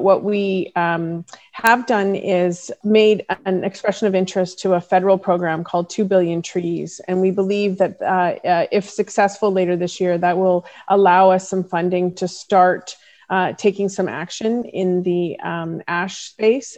Prince Edward County Council received some suggestions on how to battle the infestation of the Emerald Ash Borer at its Committee of the Whole meeting on Thursday.